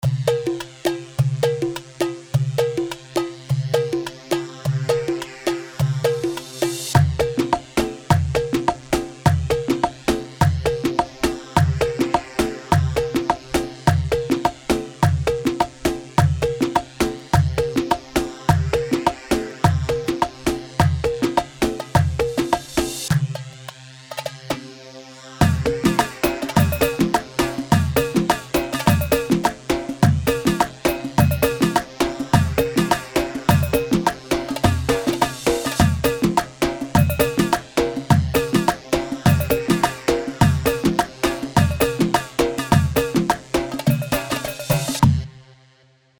Iraqi
Hewa A 4/4 104 هيوا